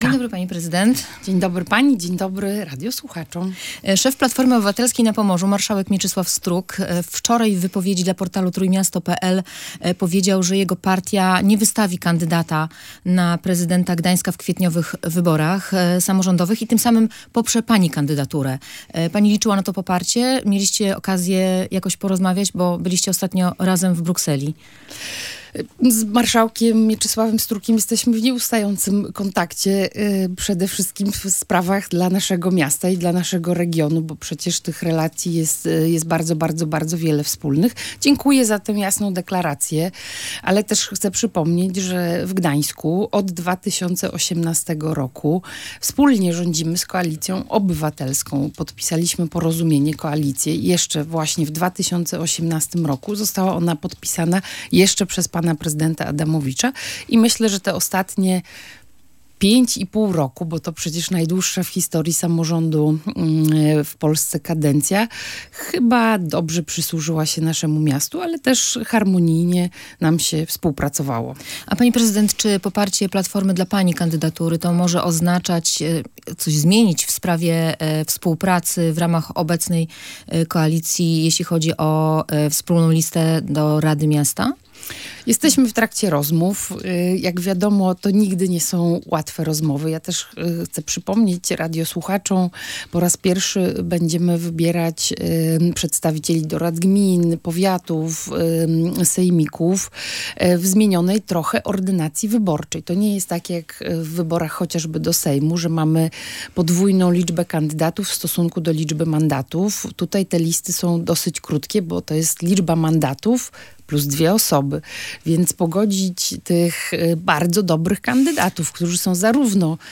Wśród tematów poruszanych w rozmowie znalazł się także system Fala, z którego od kilku dni można korzystać w gdańskich tramwajach.